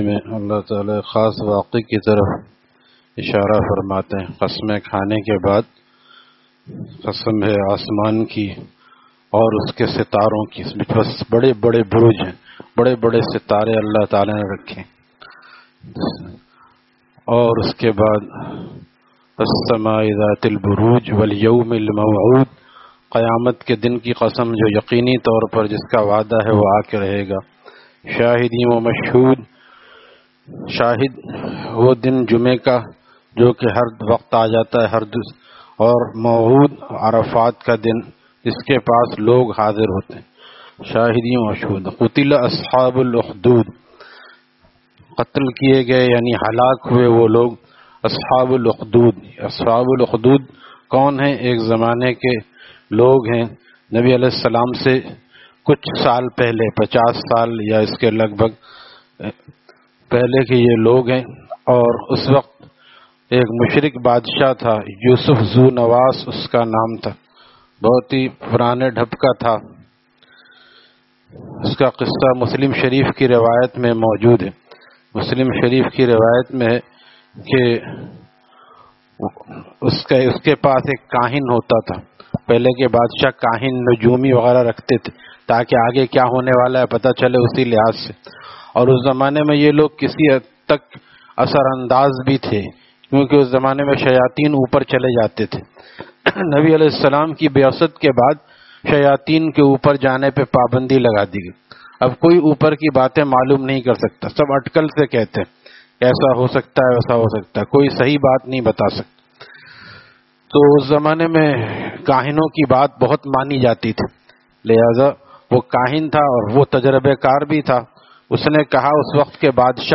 Taleem After Fajor at Jama Masjid Gulzar e Muhammadi, Khanqah Gulzar e Akhter, Sec 4D, Surjani Town